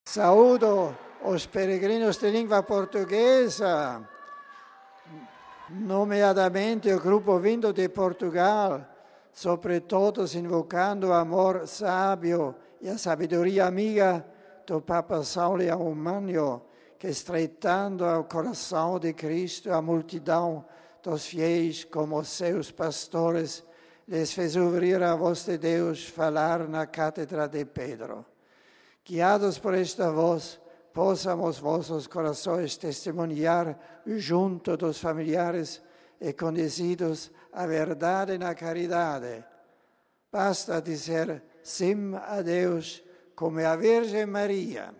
O papa falou a cerca de 20 mil pessoas divididas entre a Sala Paulo VI e a Basílica de São Pedro: ali, na breve saudação aos fiéis antes da catequese, o Santo Padre saudou com particular afeto os numerosos grupos de estudantes, fazendo votos de que eles cuidem com atenção de sua "formação integral".
Nas saudações aos diversos grupos de fiéis e peregrinos presentes, o Santo Padre dirigiu-se também aos de língua portuguesa.